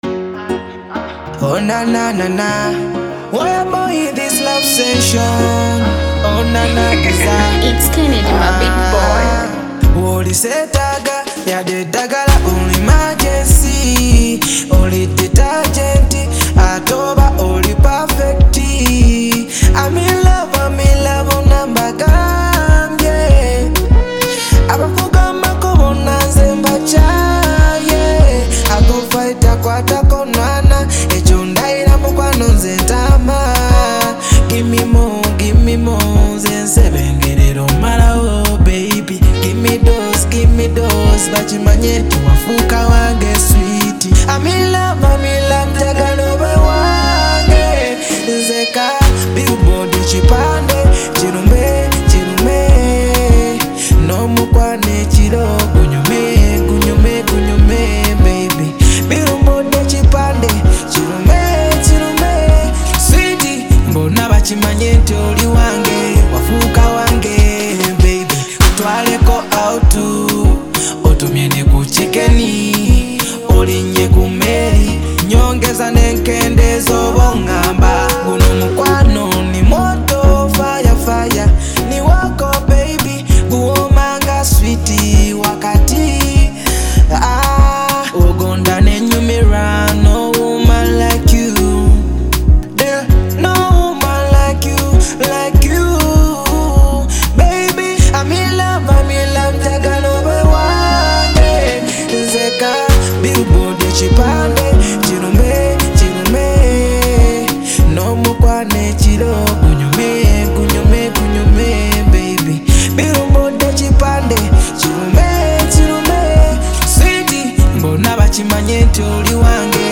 a Ugandan Afro-pop artist